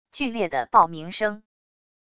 剧烈的爆鸣声wav音频